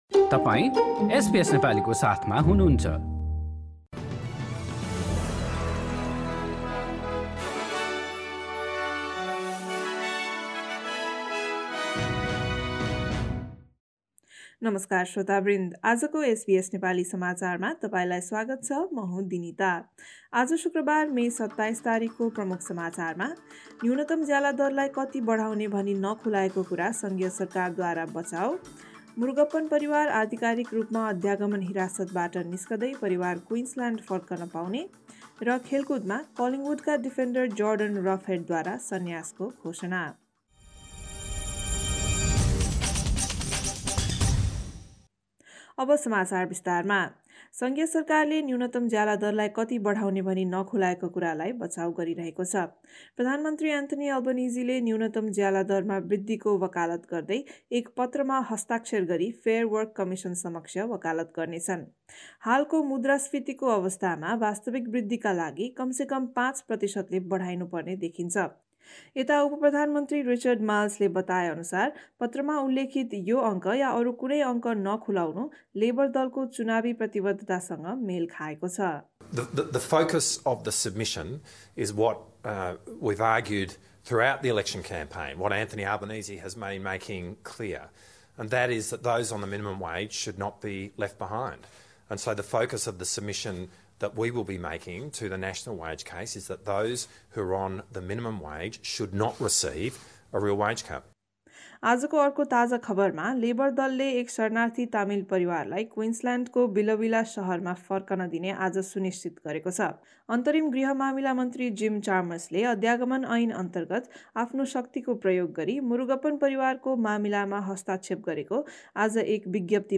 एसबीएस नेपाली अस्ट्रेलिया समाचार: शुक्रबार २७ मे २०२२